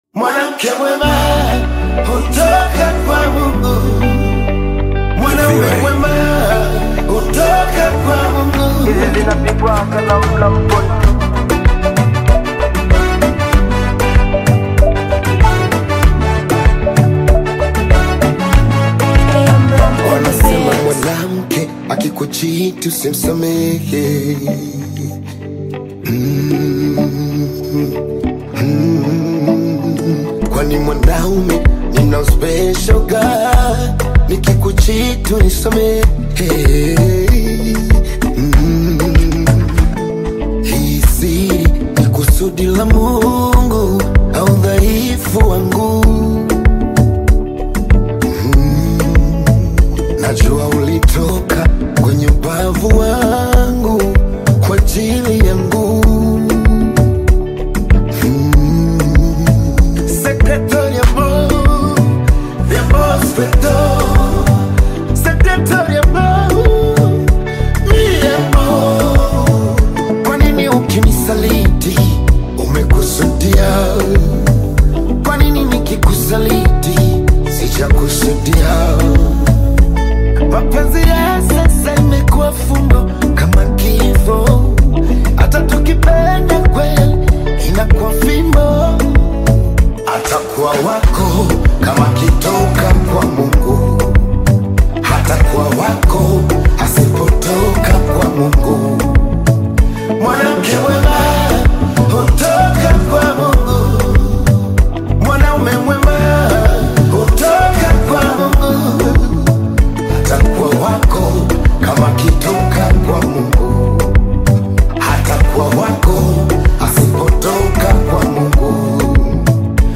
uplifting and faith-centered single